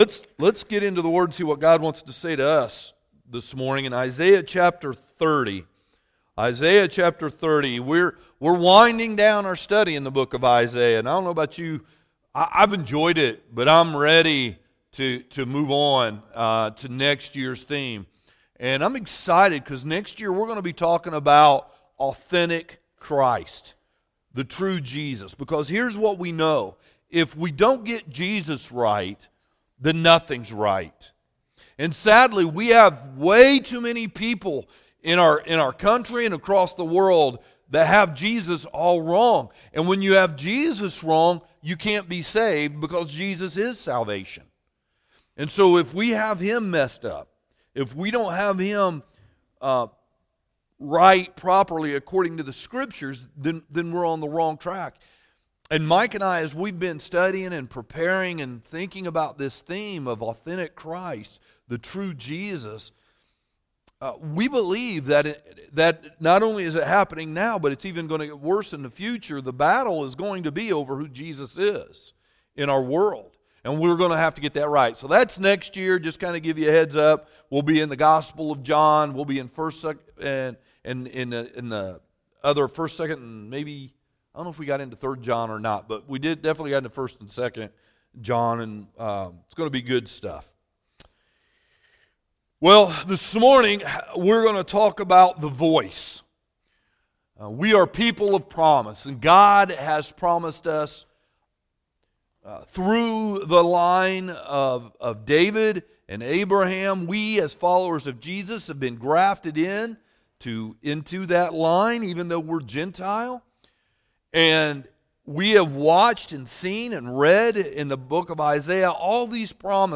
Sermons | Old Town Hill Baptist Church